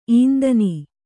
♪ īndani